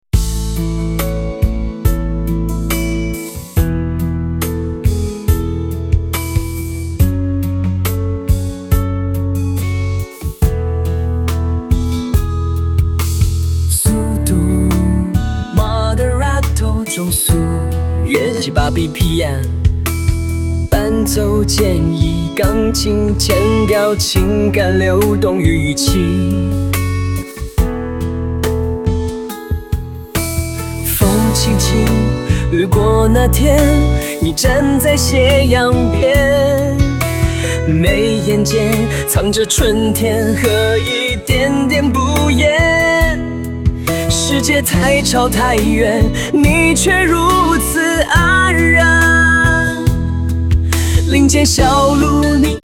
風格：抒情流行（Lyrical Pop Ballad）
調性：G大調（G Major）
拍號：4/4
速度：Moderato（中速，約 78 BPM）
伴奏建議：鋼琴＋原聲吉他，簡約編制，強調情感流動與語氣。
人工智能生成式歌曲